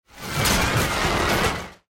File:Mutant termites worker roar.mp3
Mutant_termites_worker_roar.mp3